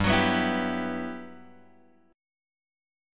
G Major 13th
GM13 chord {3 x 4 4 5 3} chord